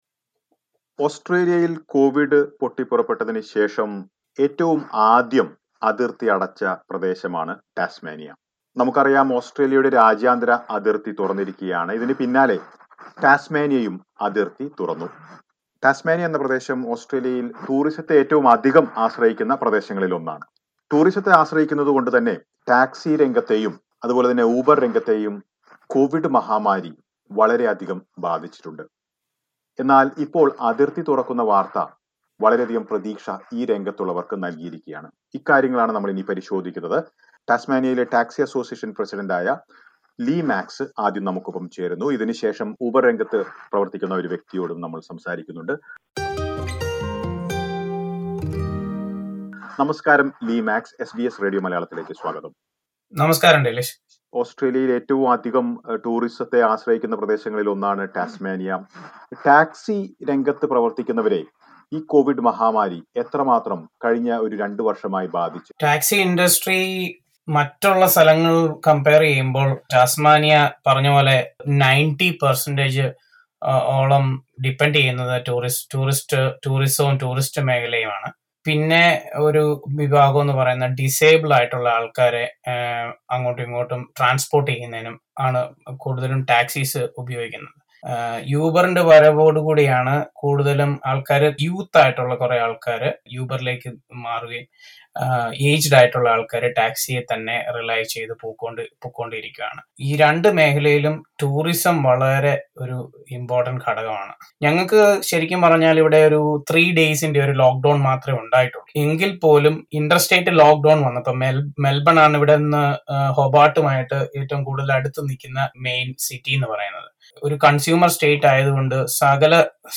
Tasmania, one of the states/territories that is heavily dependent on tourism has also reopened its borders. For many working in the taxi and uber industry, this is a much-awaited change. Listen to a report.